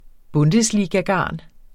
Udtale [ ˈbɔndəsˌliːga- ]